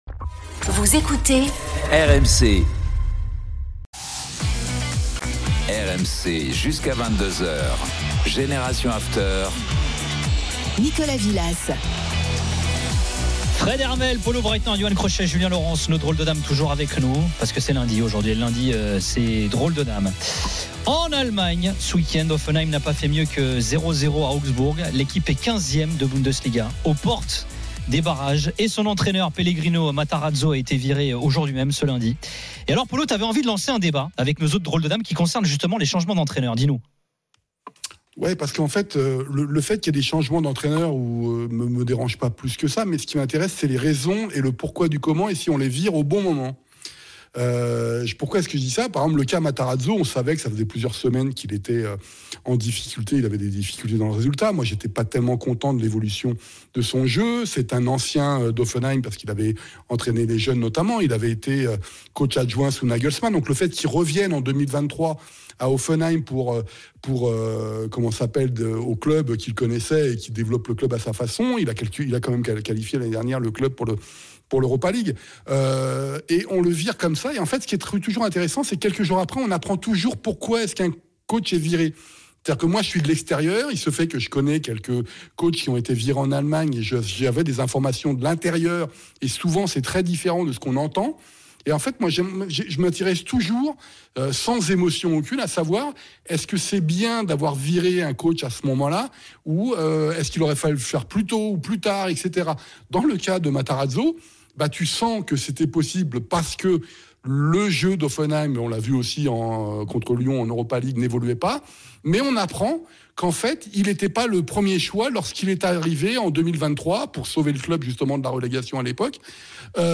Au programme, des débats passionnés entre experts et auditeurs RMC, ainsi que de nombreux invités.